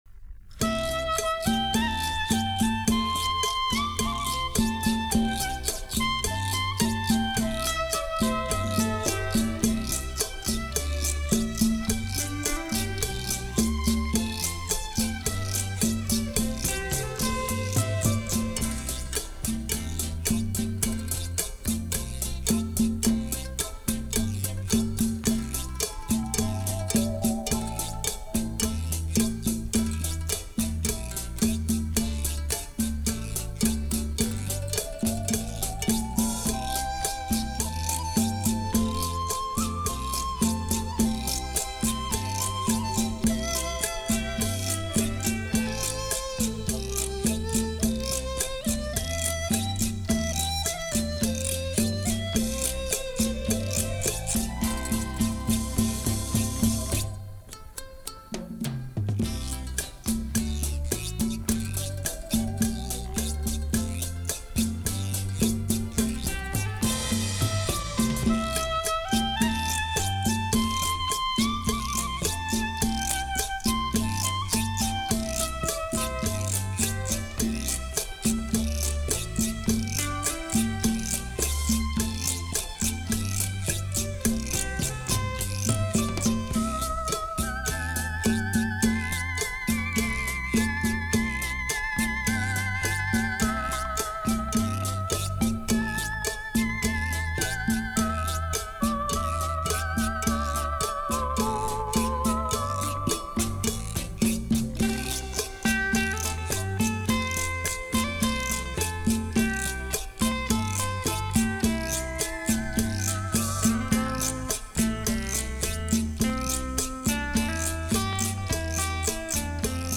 台湾乡土音乐（之二）
台湾传统乐器与西洋乐器联合演奏